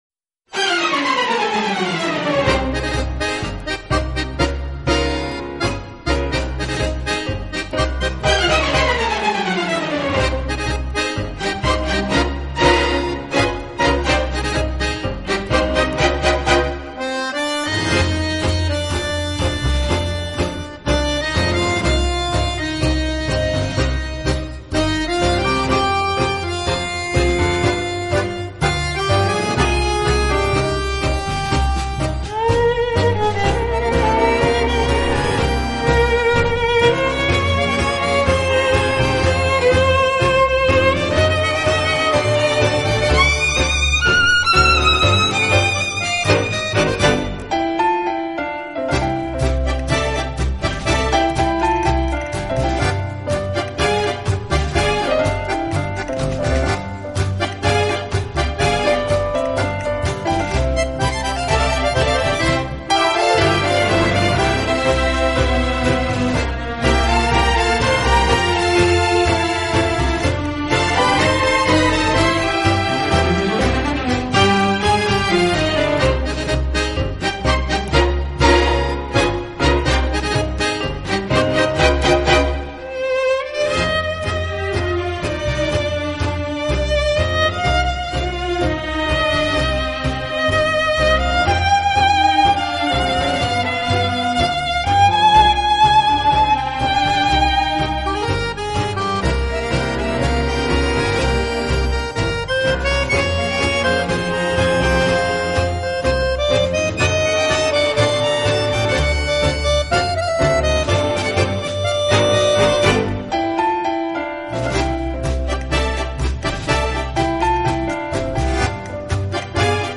【探戈纯音乐】
在跳探戈的时候节奏的偶尔停顿让舞者更加兴奋。
曲子开头通常伴有许多手风琴，这使得音乐在某种程度上稍具古风。